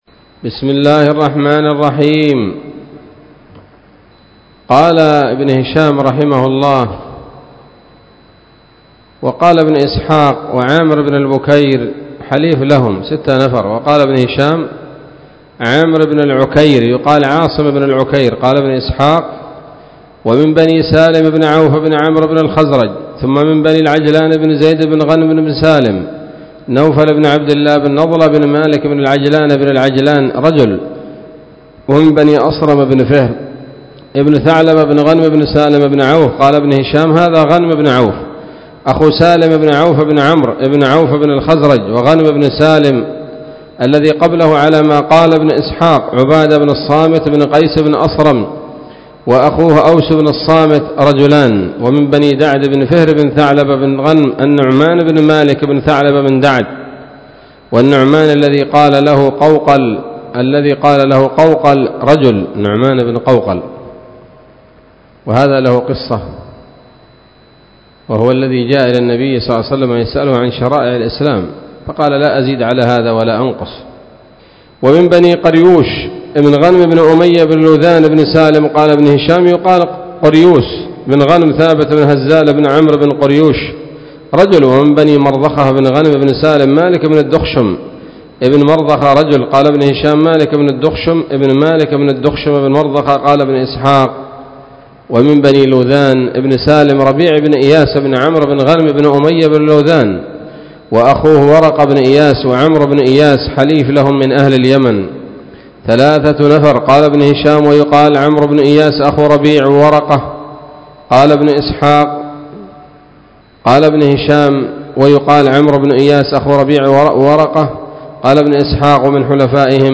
الدرس السابع والثلاثون بعد المائة من التعليق على كتاب السيرة النبوية لابن هشام